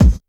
Kick_43.wav